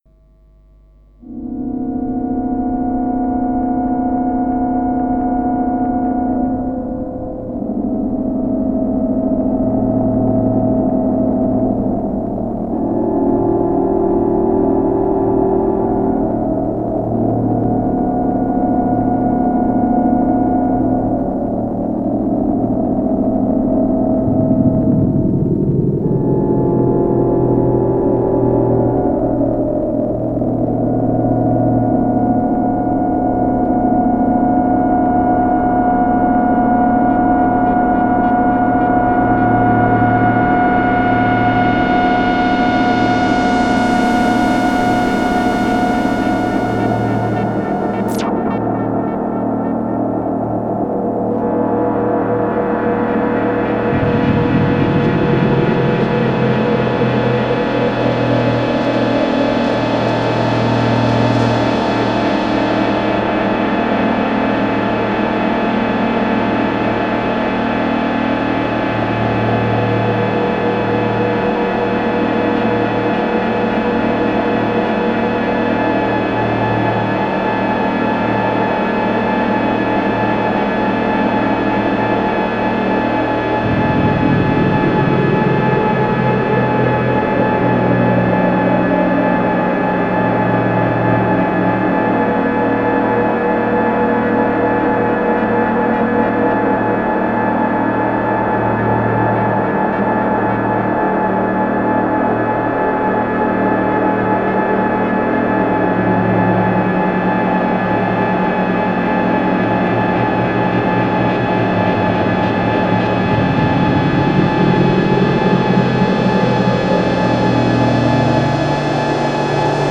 Crumar DS2
Some technical information: It is a 2 oscillator monophonic synth with pretty advanced bizarre modulation capabilities (due to its 2 LFO’s with different waveforms including a weird cascading staircase wave ) Add to that a fully polyphonic polysection which goes through the filter and is also modulatable by the LFOs – You can layer the monophonic and poly section together for giant walls of sound – Crumar also released the DS-1 synthesizer which is basicly the same synthesizer without the  polyphonic section…but I have never seen one of those and think they are pretty rare.
Its big, unstable, adventerous, bleepy, moist, crackling,  cloudy, hoarse, droney…check out these mp3 sounddemos and listen for yourself!